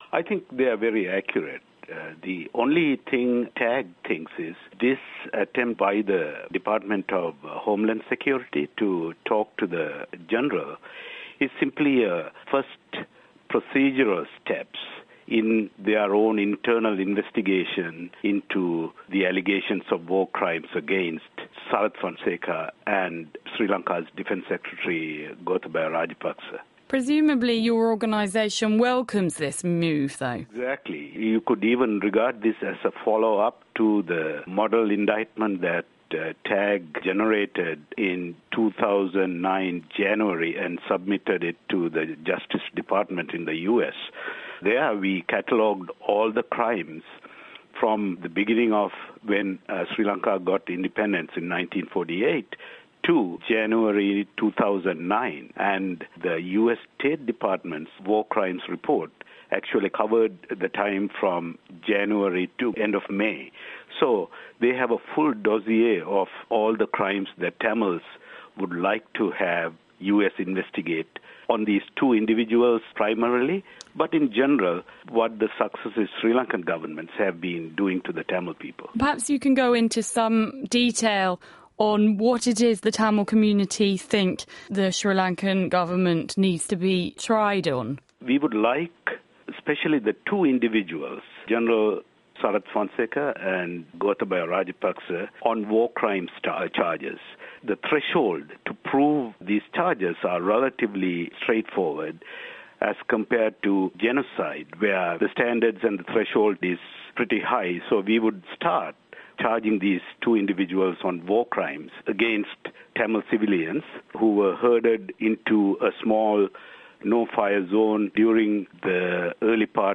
PDF IconTAG interview with Radio NL on Fonseka